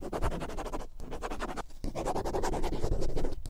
Pen Writing On Paper